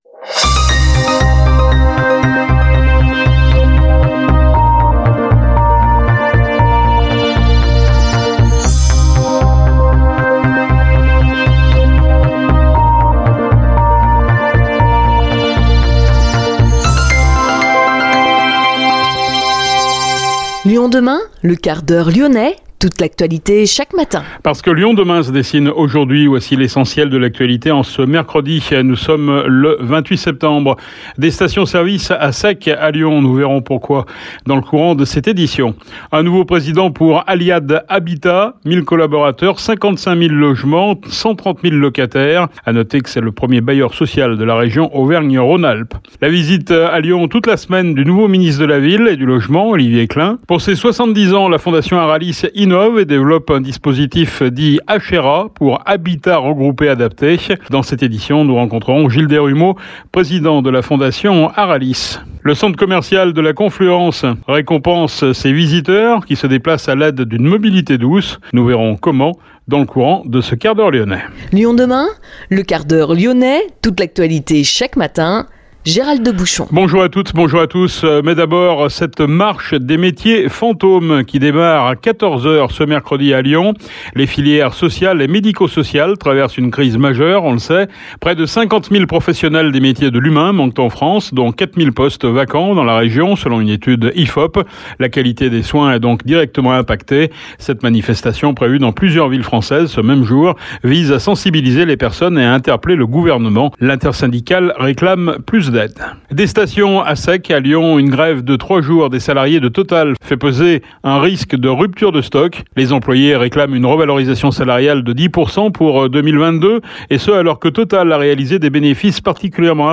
Interview à écouter dans le 1/4H LYONNAIS.